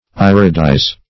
Search Result for " iridize" : The Collaborative International Dictionary of English v.0.48: Iridize \Ir"i*dize\, v. t. [imp.